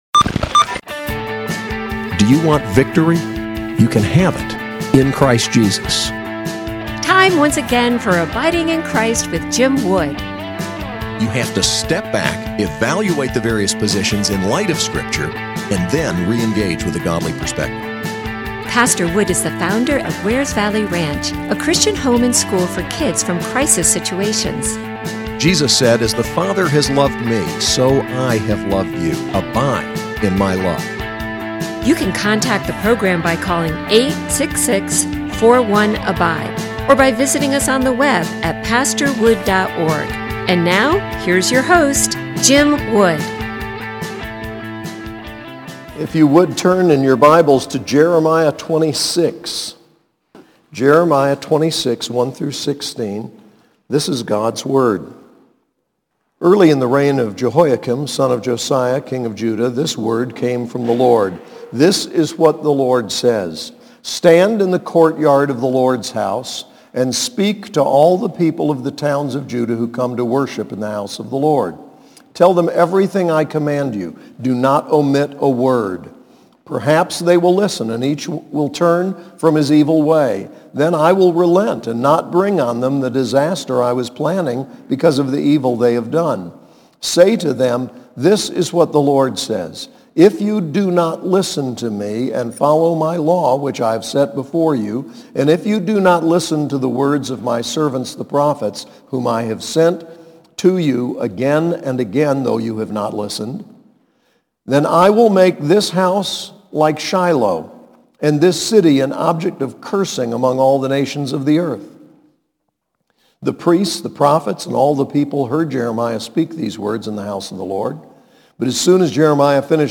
SAS Chapel: Reformation, Jan Hus